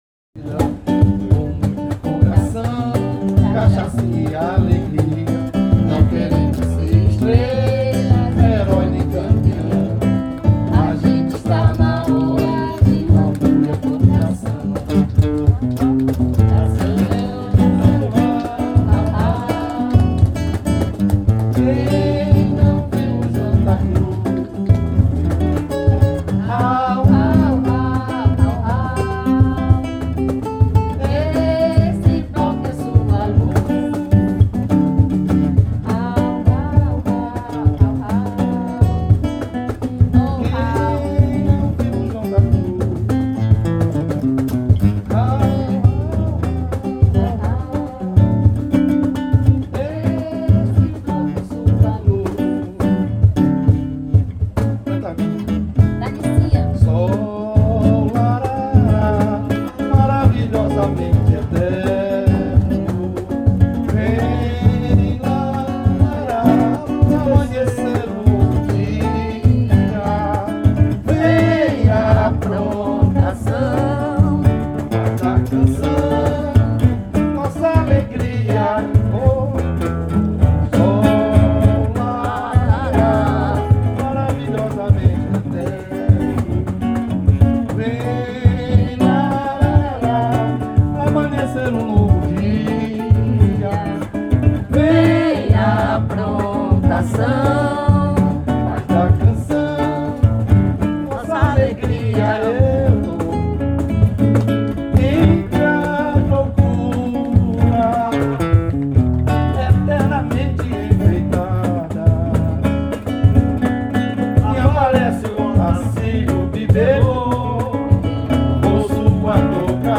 04:05:00   Samba Enredo
Contra Surdo
Violao Acústico 6
Cavaquinho
Pandeiro
Tamborim, Voz
Surdo, Voz